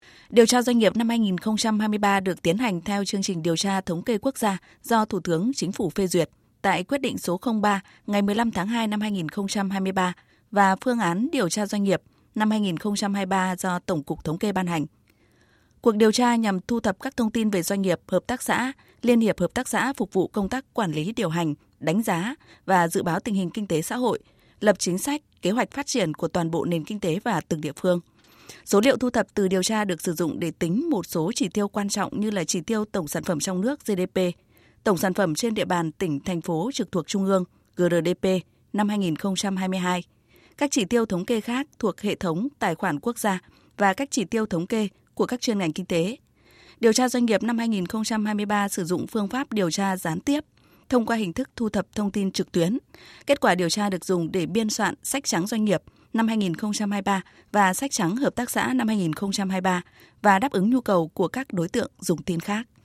Từ 1/4/2023, Tổng cục Thống kê, Bộ Kế hoạch và đầu tư tiến hành điều tra doanh nghiệp trên phạm vi 63 tỉnh, thành phố trực thuộc trung ương theo hình thức trực tuyến. Các đơn vị thuộc diện điều tra gồm: 64 tập đoàn, Tổng công ty; doanh nghiệp; hợp tác xã và các chi nhánh hạch toán độc lập trực thuộc doanh nghiệp. Phóng viên